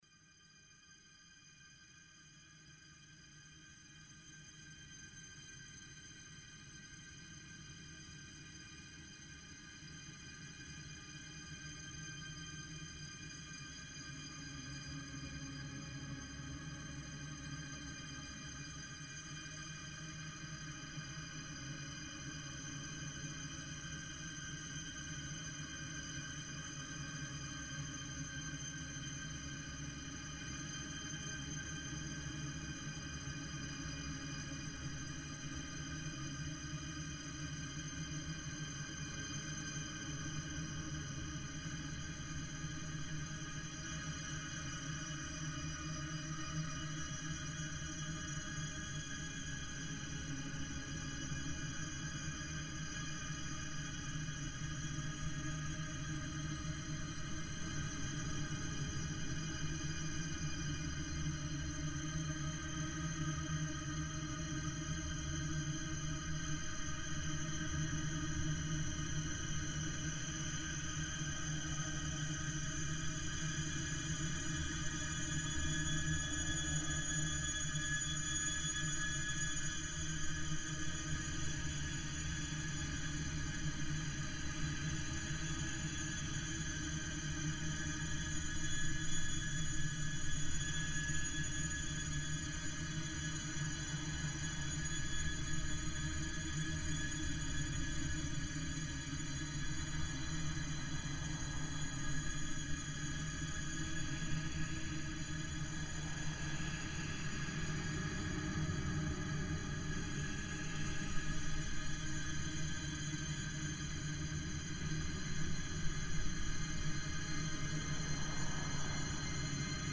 Berlin sound walk 2011 … we can see another plane … 3:26 (headphones recommended)